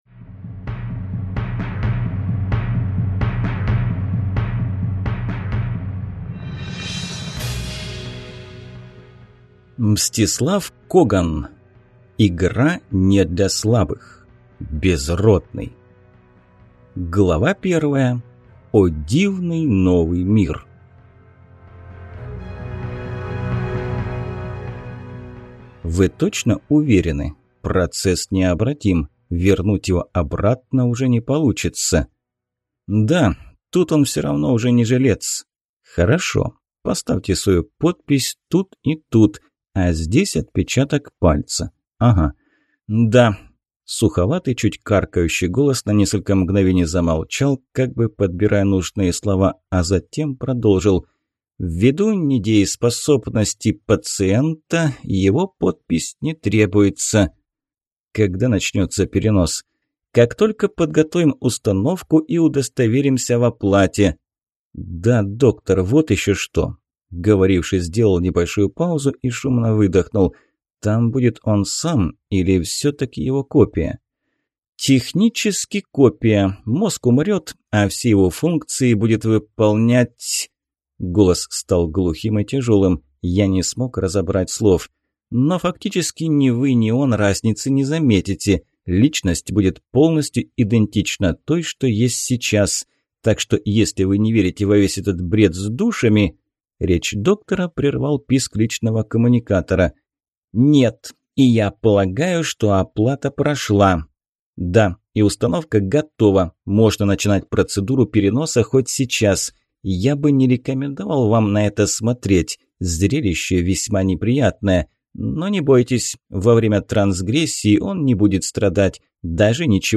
Аудиокнига Игра не для слабых: Безродный | Библиотека аудиокниг